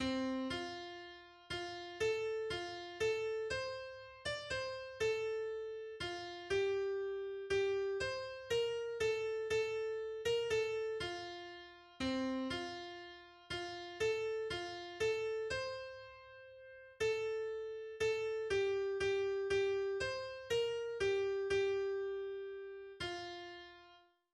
Schlaflied
schlesisches Volkslied